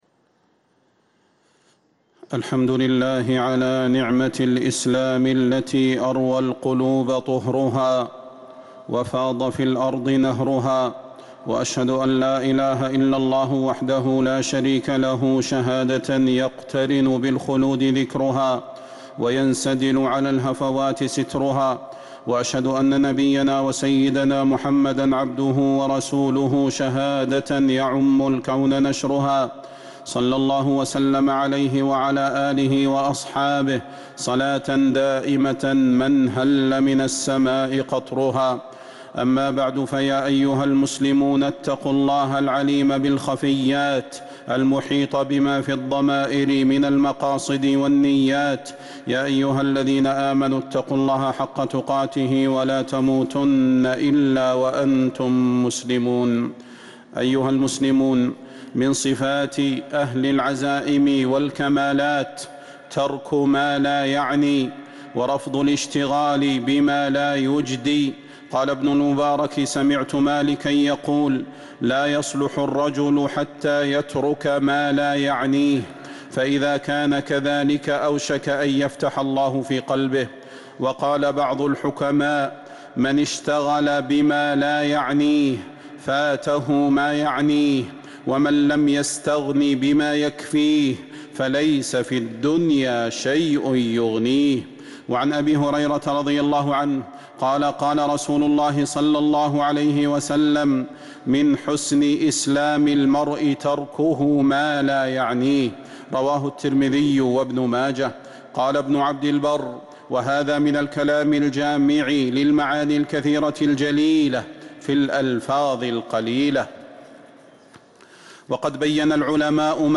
خطبة الجمعة 4 ذو القعدة 1446هـ | Khutbah Jumu’ah 2-5-2025 > خطب الحرم النبوي عام 1446 🕌 > خطب الحرم النبوي 🕌 > المزيد - تلاوات الحرمين